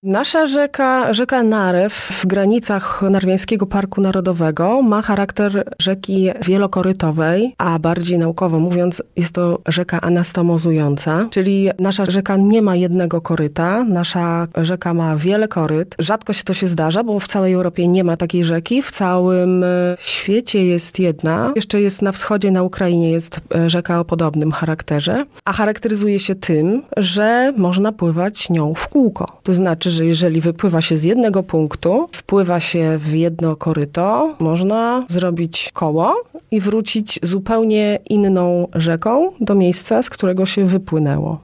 zachęcała na antenie Radia Nadzieja